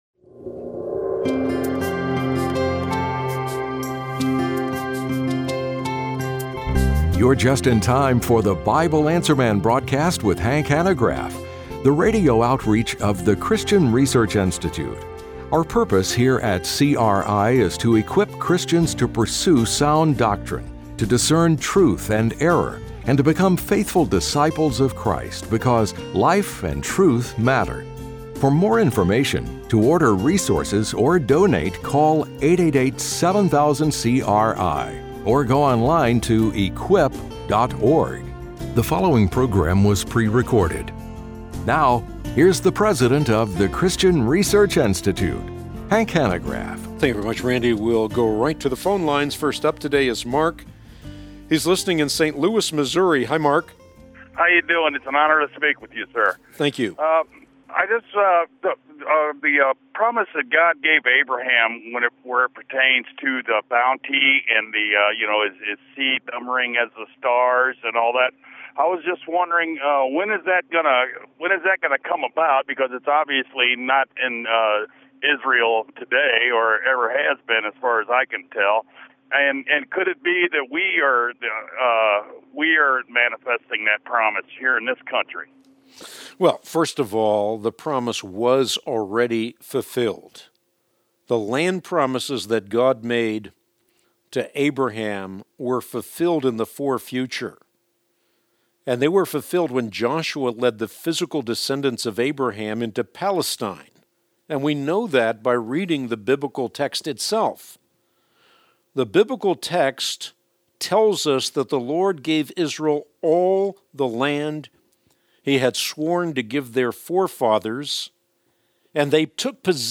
On today’s Bible Answer Man broadcast (01/20/26), Hank answers the following questions: